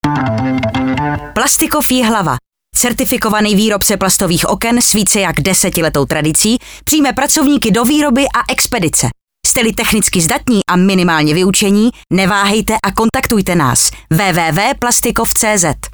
Slogan na radiu Vysočina č.4 - nabídka zaměstnání